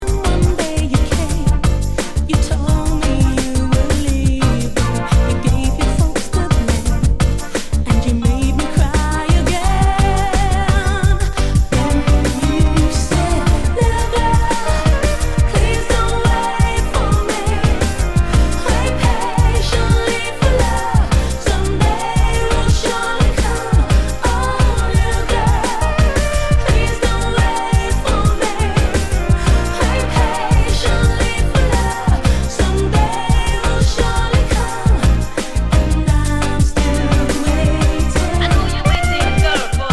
ラヴァーズレゲエの愛らしさとUKプラックの要素を併せ持った、メロディ展開も最高の楽曲
Tag       R&B R&B